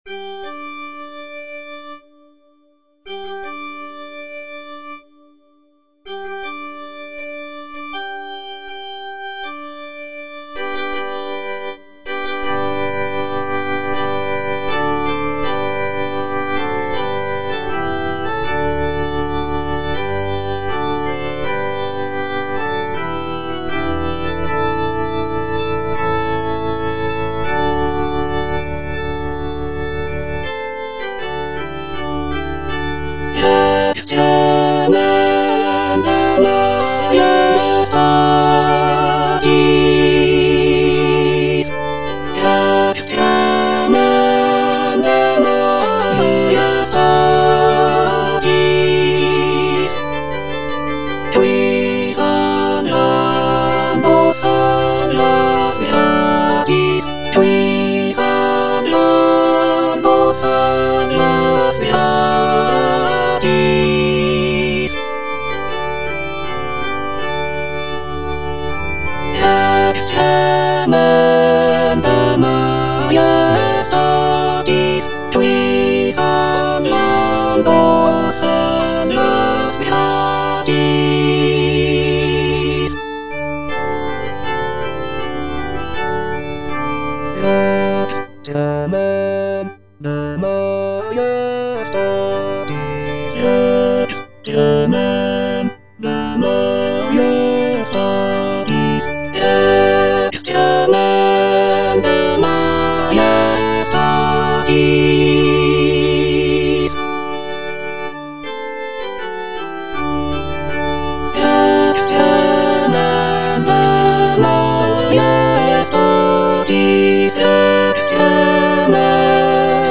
(alto II) (Emphasised voice and other voices) Ads stop
is a sacred choral work rooted in his Christian faith.